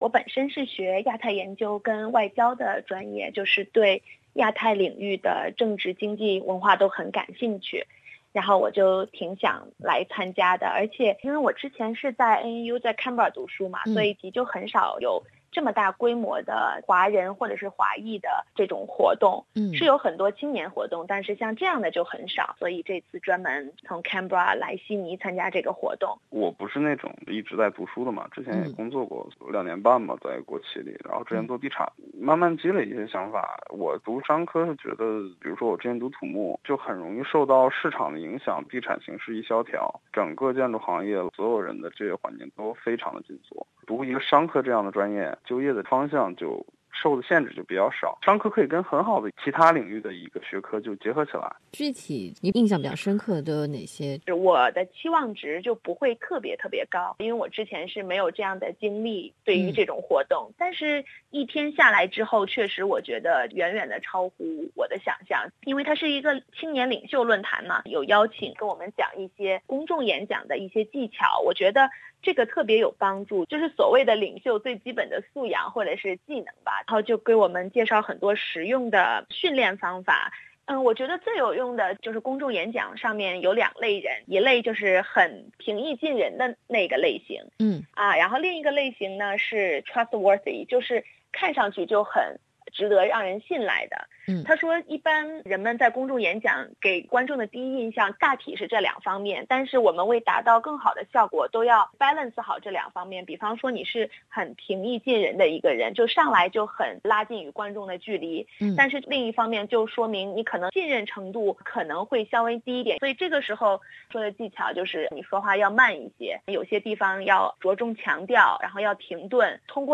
SBS普通话记者采访了两位，他们首先介绍了自己的专业背景：